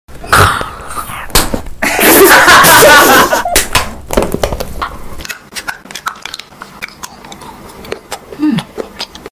Random Color food mukbang 💙🤤 sound effects free download